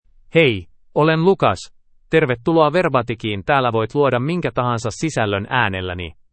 Lucas — Male Finnish AI voice
Voice sample
Male
Lucas delivers clear pronunciation with authentic Finland Finnish intonation, making your content sound professionally produced.